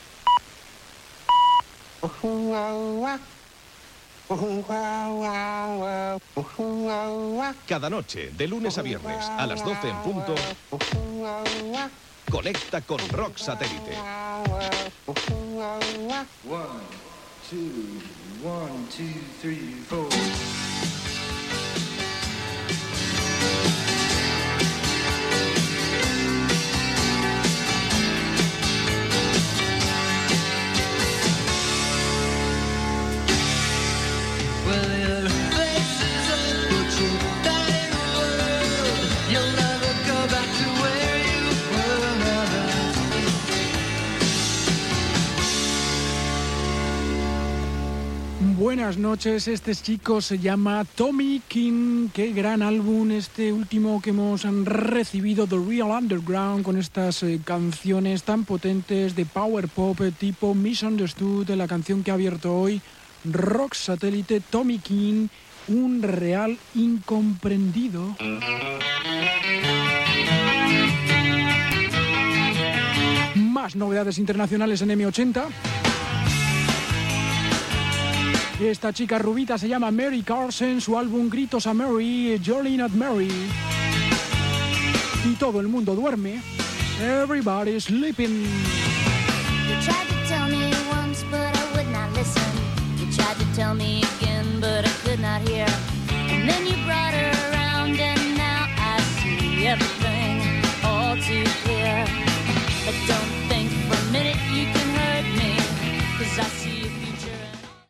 Careta del programa, tema musical, comentari de l'artista que s'ha escoltat, tema musical
Musical